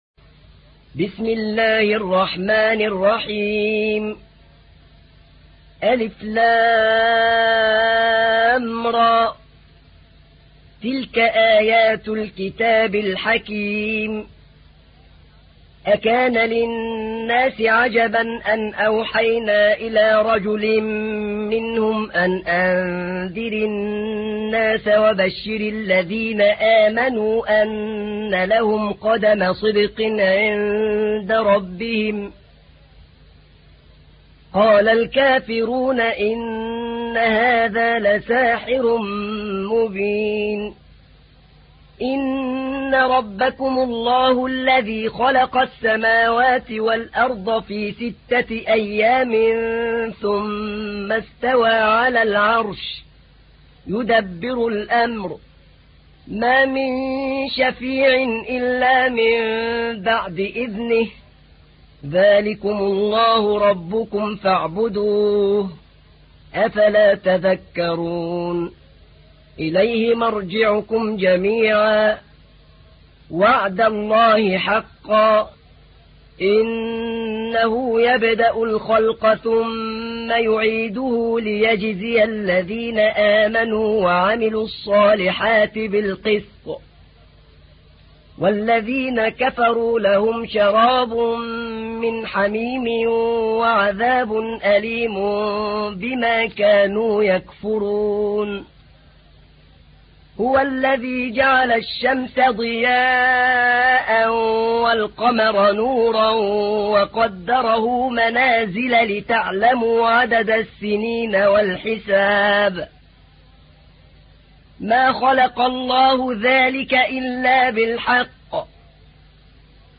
تحميل : 10. سورة يونس / القارئ أحمد نعينع / القرآن الكريم / موقع يا حسين